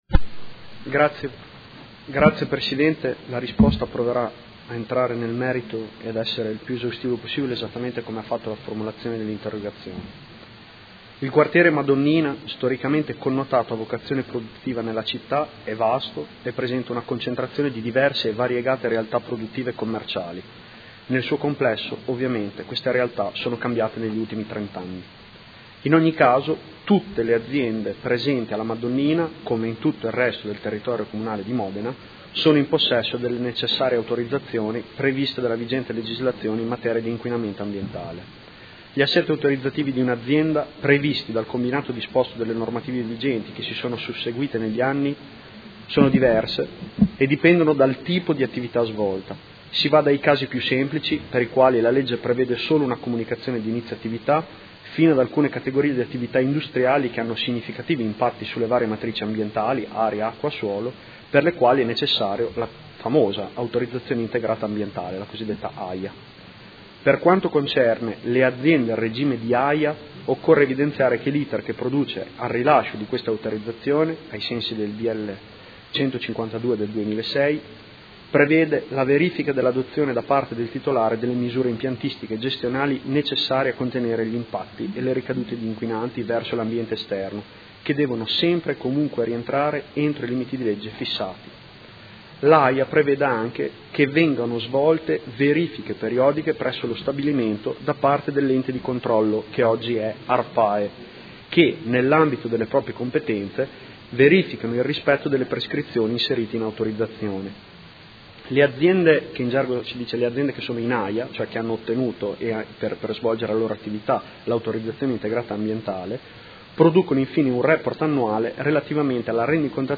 Seduta del 24/11/2016 Risponde a Interrogazione dei Consiglieri Pacchioni e De Lillo (PD) avente per oggetto: Quartiere Madonnina – Cattivi odori, rilevamenti, monitoraggi e comunicazione